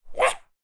femalezombie_attack_02.ogg